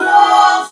LOVE VOX  -L.wav